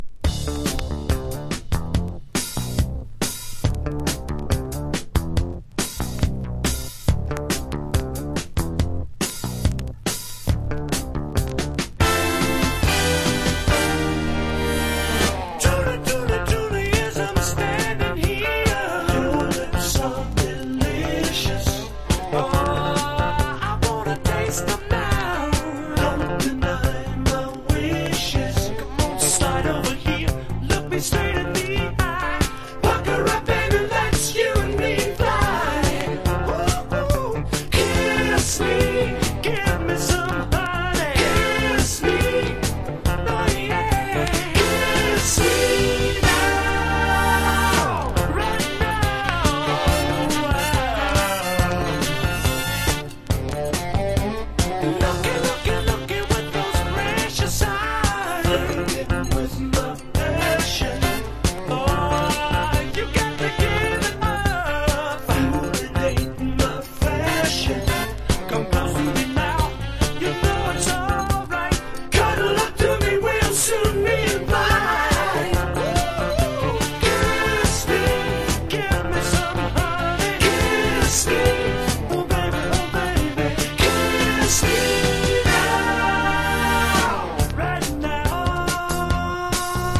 FUNK / DEEP FUNK# DISCO